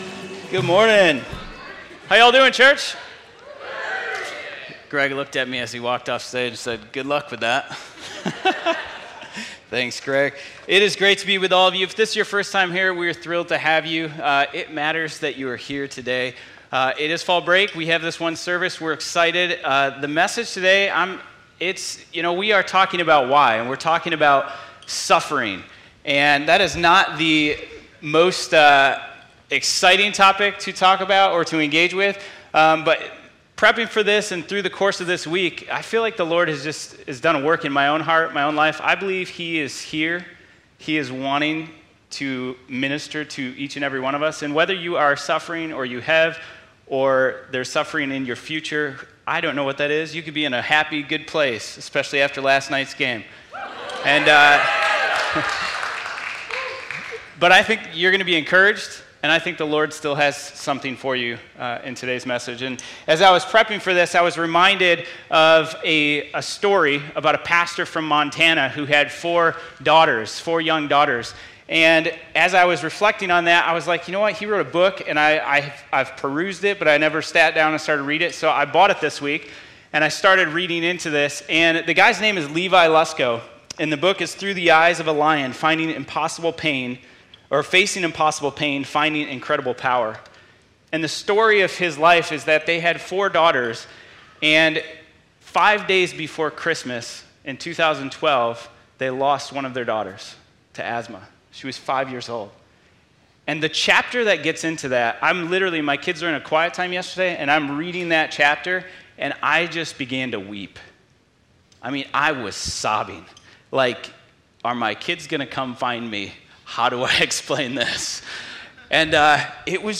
Audio Sermon Save Audio https